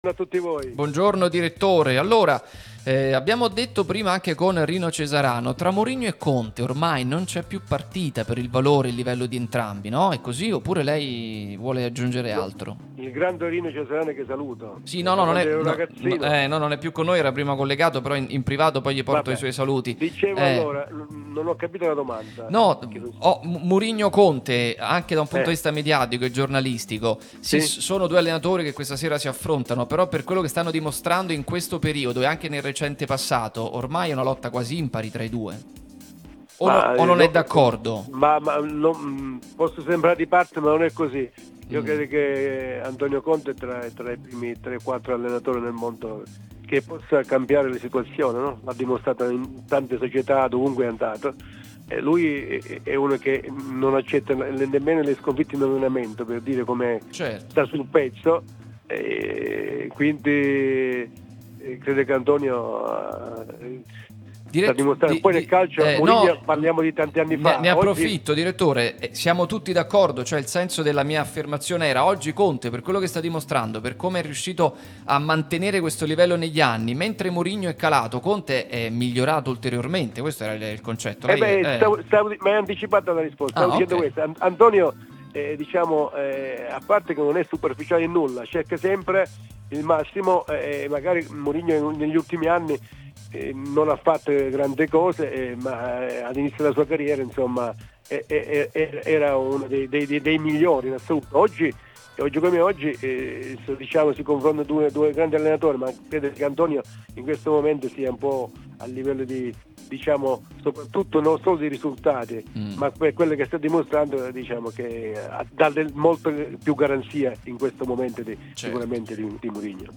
Pausa Caffè, trasmissione in onda 11-13 sulla nostra Radio Tutto Napoli